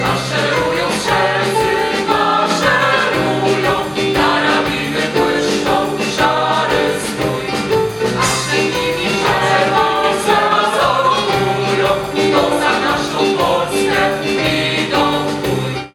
Był to koncert niezwykły, bo na scenie mogliśmy zobaczyć Chór Policyjny Garnizonu Warmińsko-Mazurskiego.
piosenka.mp3